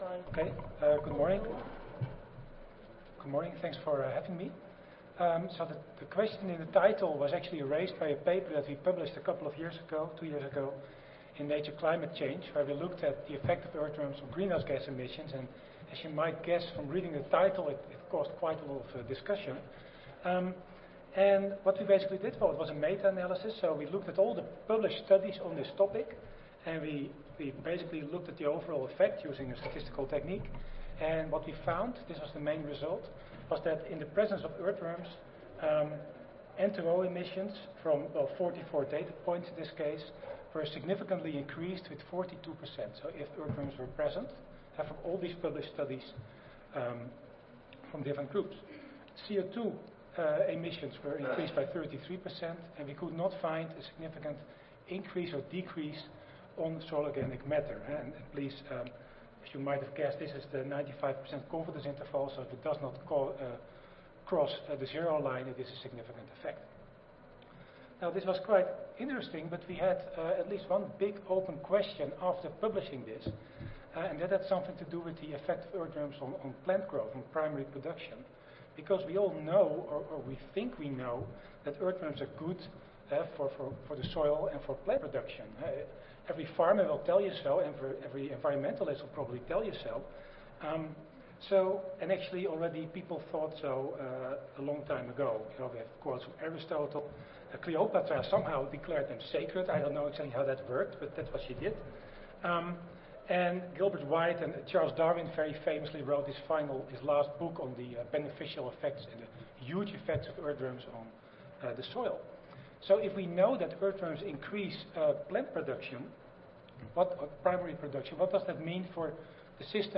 Northern Arizona University Audio File Recorded Presentation